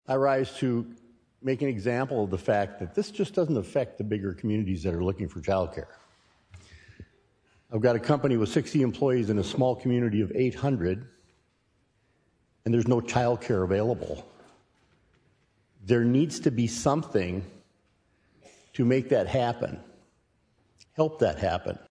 Rep. William Shorma, R-Dakota Dunes, supported the measure, citing economic development needs.